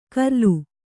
♪ karlu